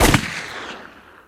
Gunshot (3).wav